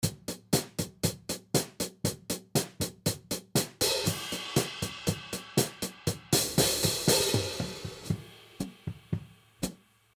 Hi-Hat Mic: